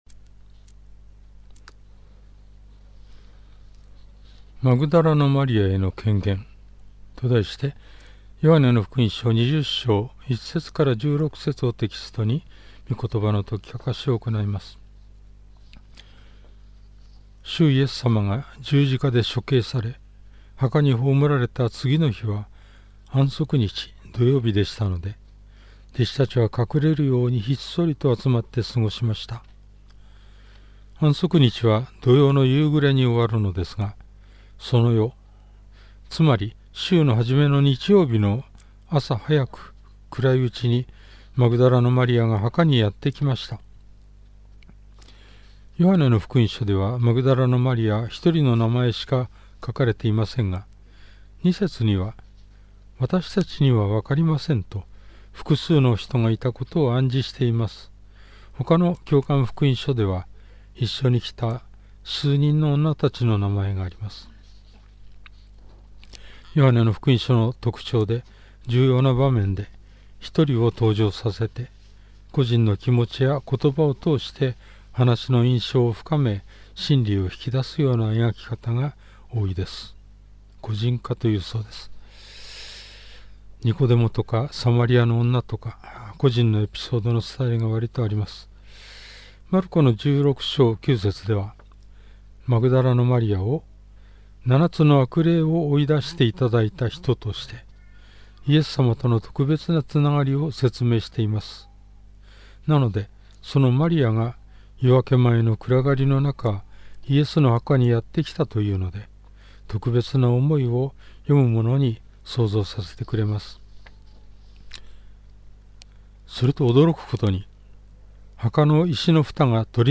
主日礼拝
説教
♪ 事前録音分